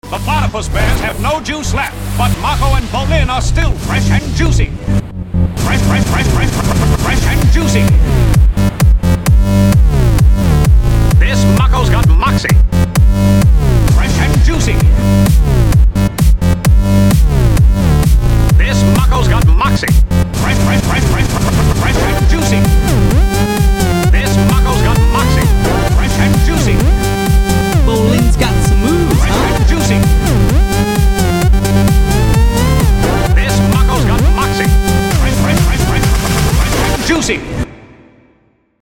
Electro dance remix